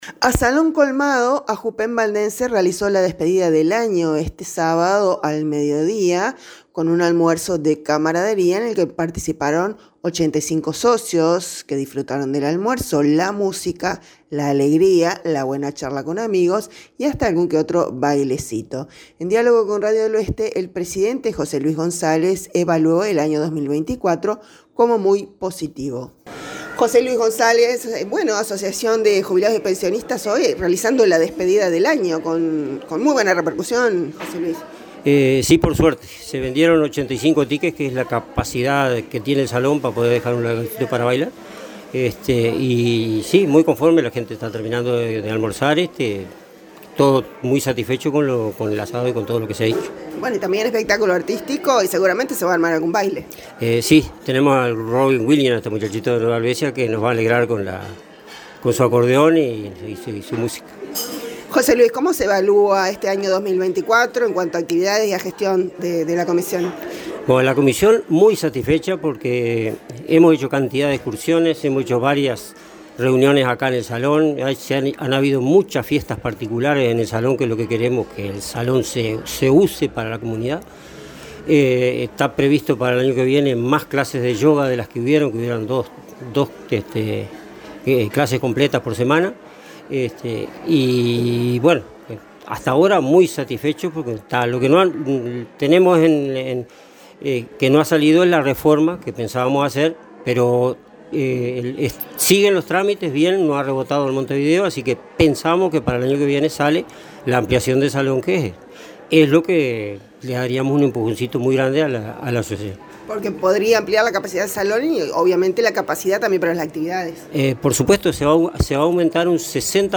En diálogo con Radio del Oeste